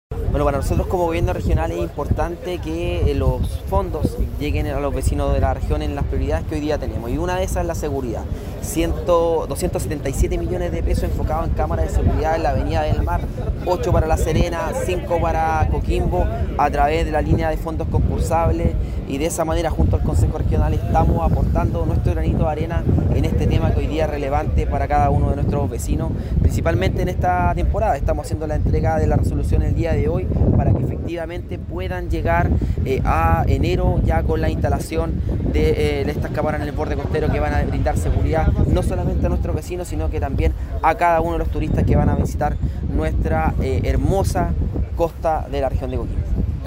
GOBERNADOR-DARWIN-IBACACHE.mp3